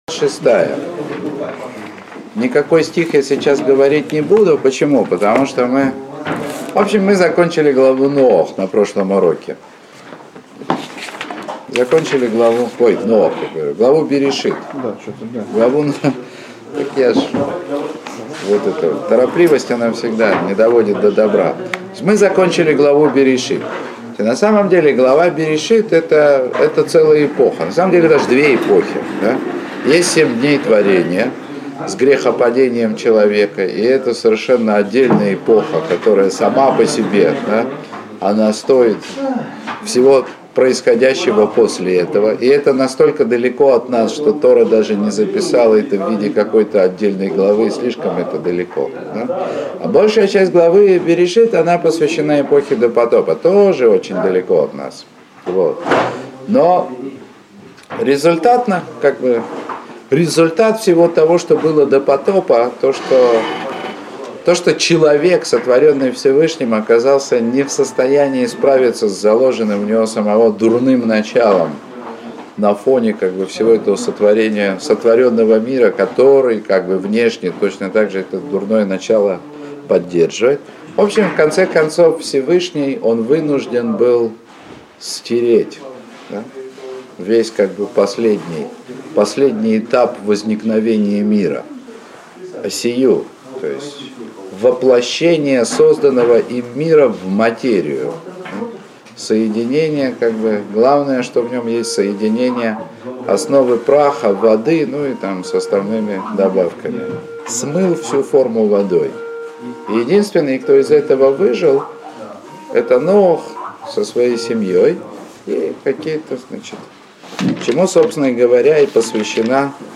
Уроки по книге Берейшит.